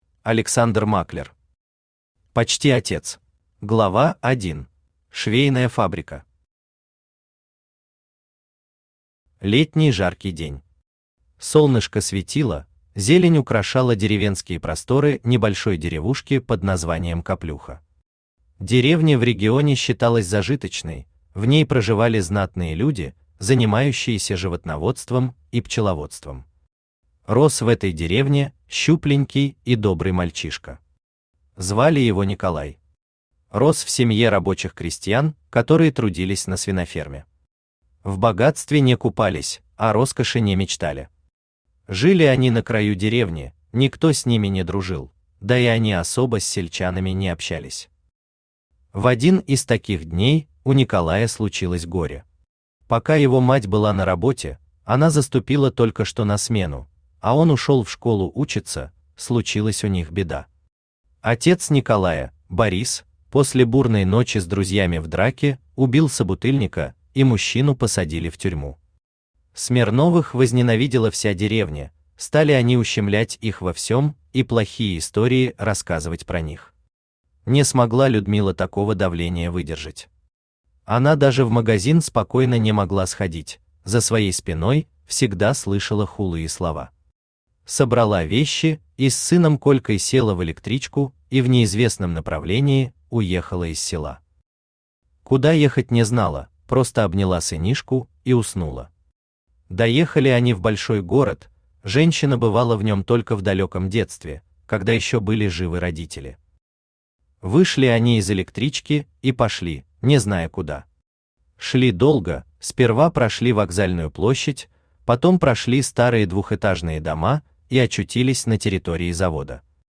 Аудиокнига Почти отец | Библиотека аудиокниг
Aудиокнига Почти отец Автор Александр Германович Маклер Читает аудиокнигу Авточтец ЛитРес.